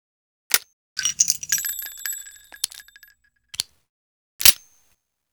🌲 / midnight_guns mguns mgpak0.pk3dir sound weapon magnum
webley_reload_REPLACEME.wav